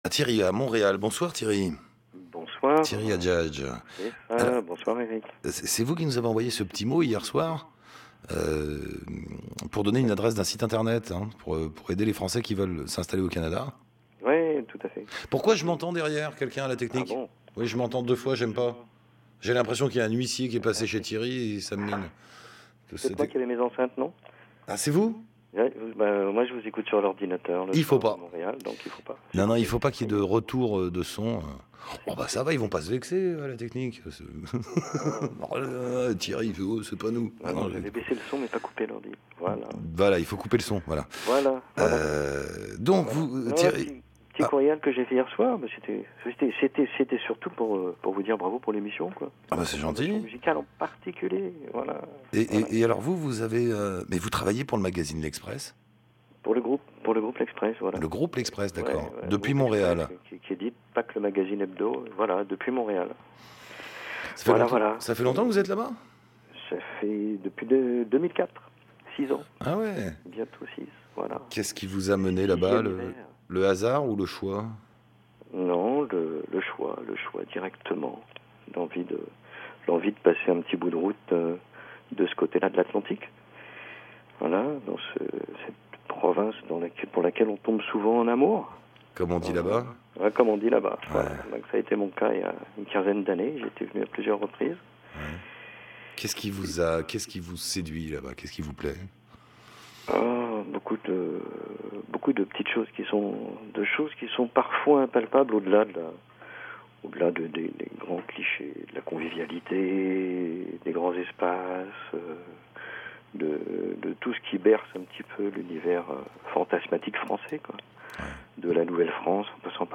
Une interview qui reflète le contenu de la FAQ.